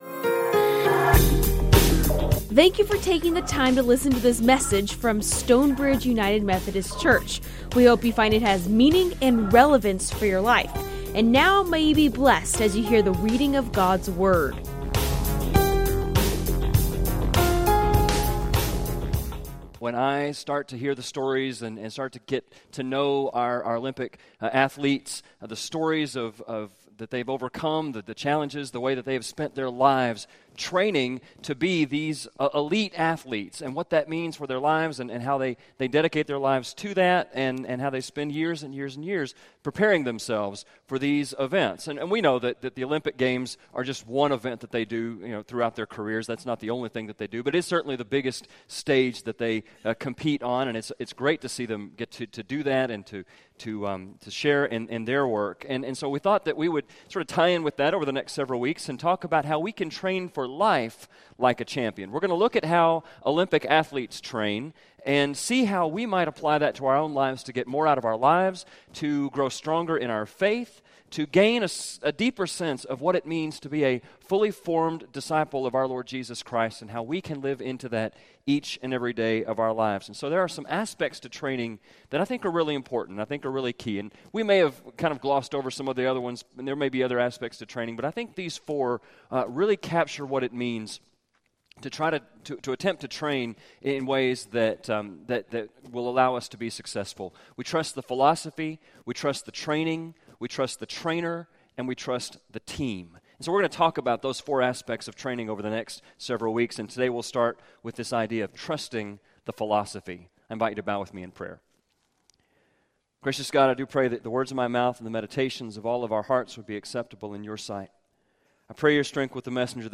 Recorded live at Stonebridge United Methodist Church in McKinney, TX.
2-9-14 Sermon - Celebration.mp3